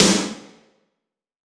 SNARE 086.wav